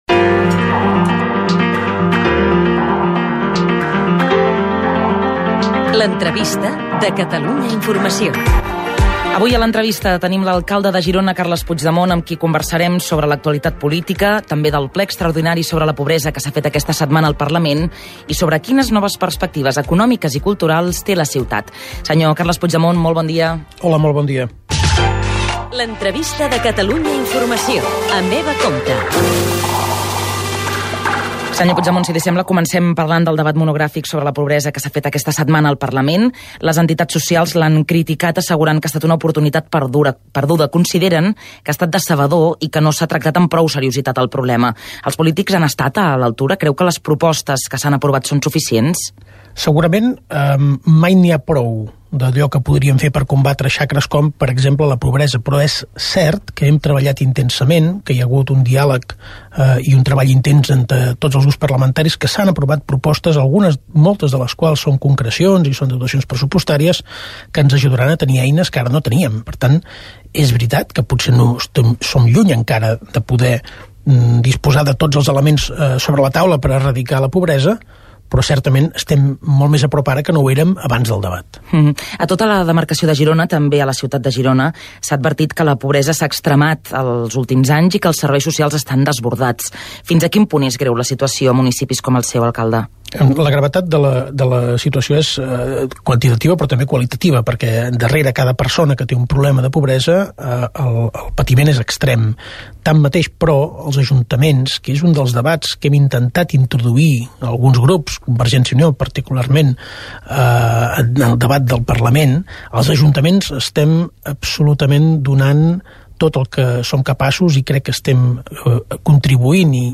Careta del programa, presentació de l'entrevista informativa a l'alcalde de Girona Carles Puigdemont, indicatiu del programa, preguntes sobre el debat de la pobresa fet al Parlament de Catalunya, reforma de la Llei d'administració local, etc.
Informatiu